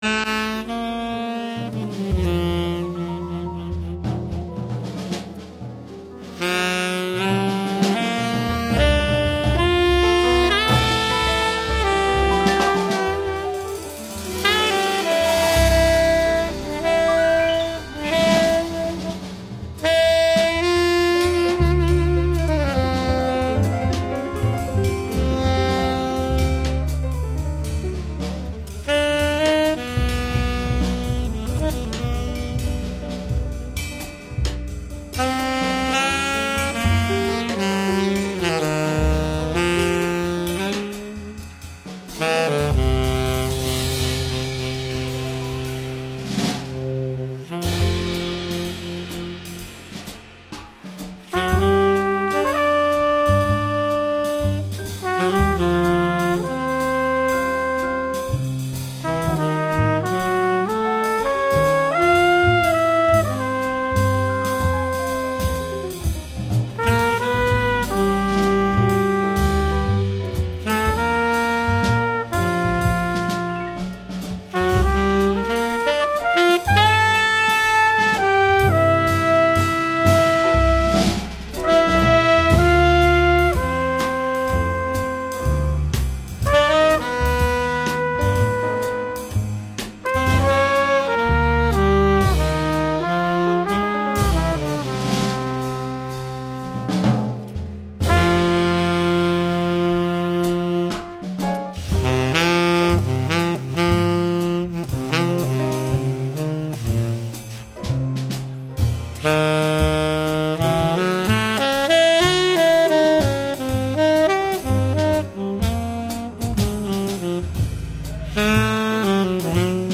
Genre:Gospel, Christian, Talk